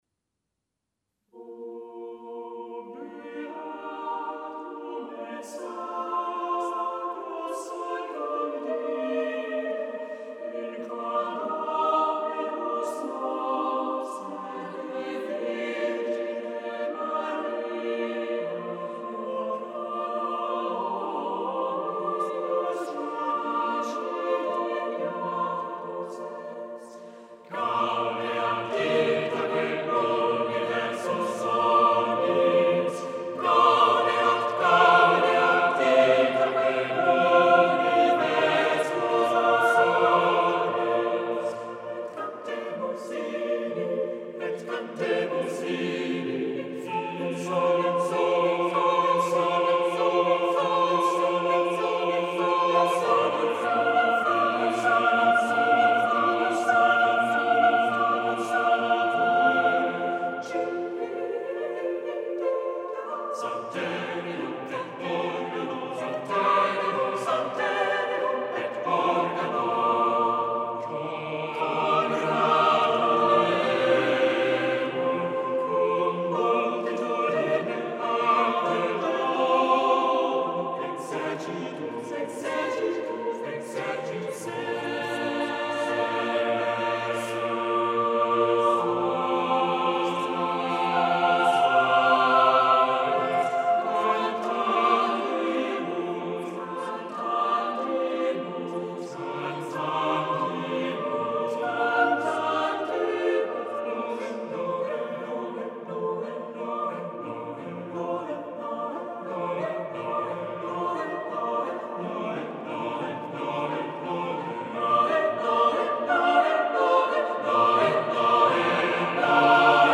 Listen to the Cambridge Singers perform "O Beatum et Sacrosanctum Diem" by Peter Philips (1561-1628).
O Beatum et Sacrosanctum Diem for 5 voices.mp3